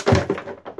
爆破声 " Cayendo
标签： 下降 下降 香椿
声道立体声